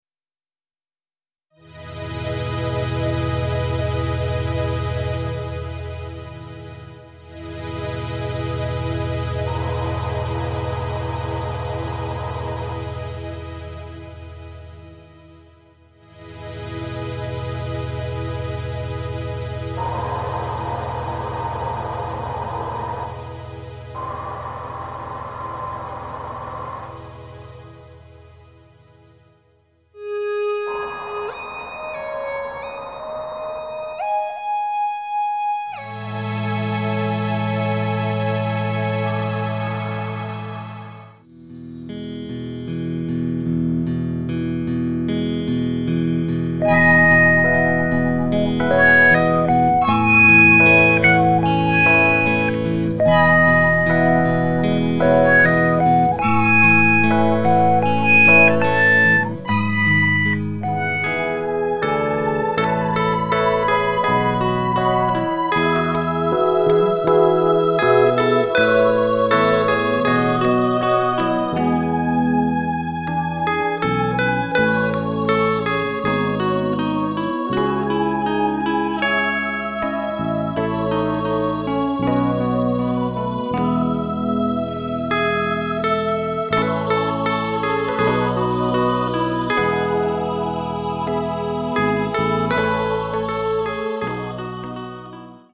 テクノ風あるいはプログレ風味なシンセ音とギターで演奏しています。
なお全曲インストです。
イントロはシンセストリングスの静謐とした雰囲気で始まります。
中盤ではワウギターがメロディを弾いています。